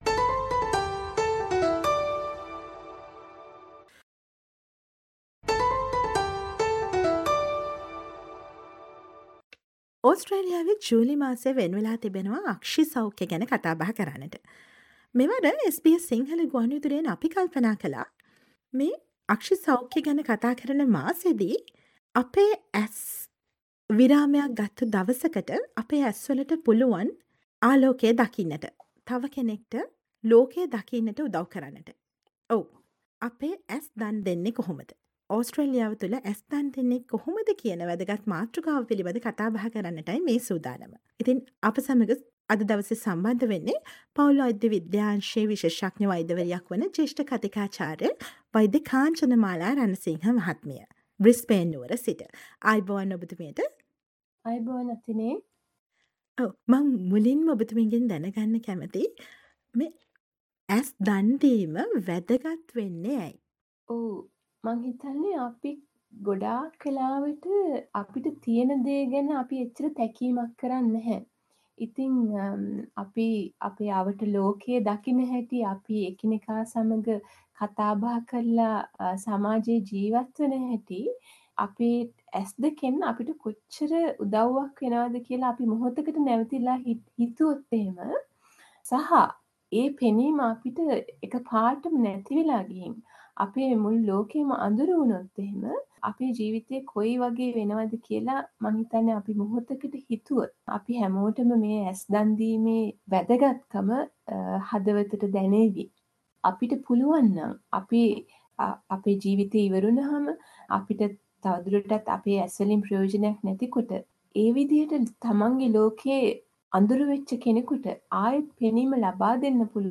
SBS සිංහල ගුවන් විදුලිය සිදු කළ සාකච්ඡාව